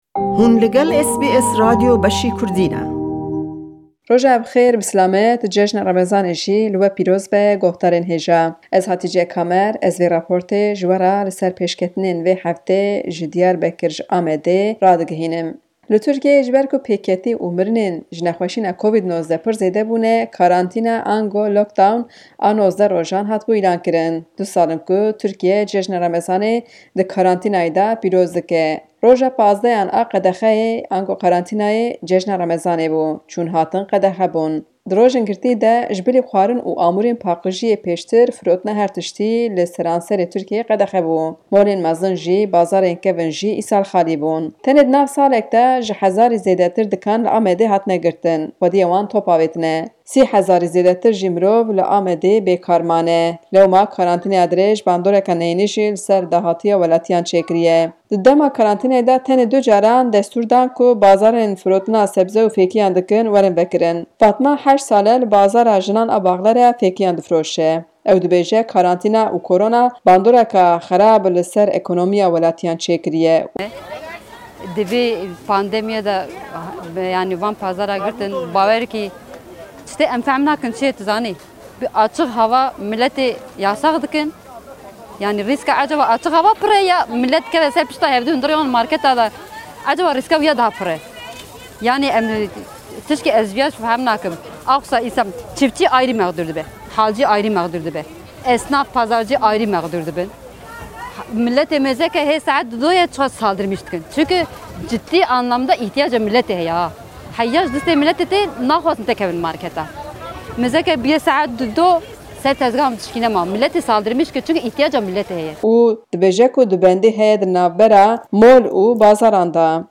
Amed/Diyarbakir Source: Wikipedia\CC BY-SA 3.0